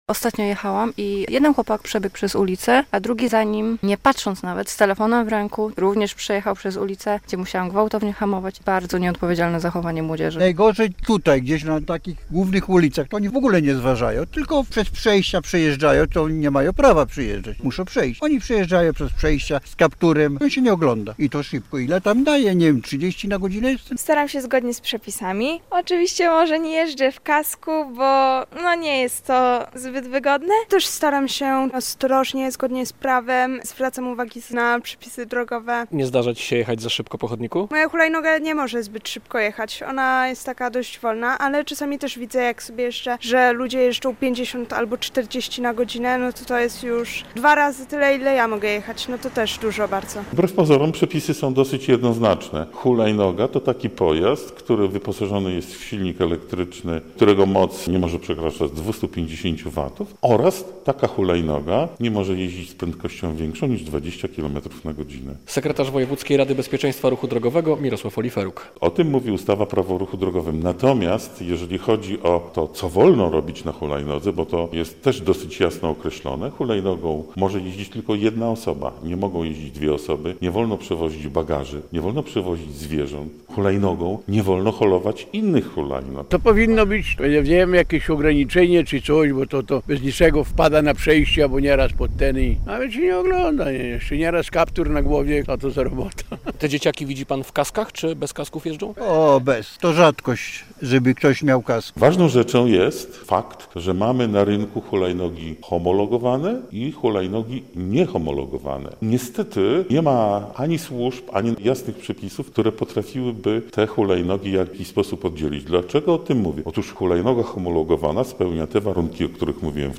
Niebezpieczne hulajnogi - relacja
I do tego bez kasków - mówią kierowcy.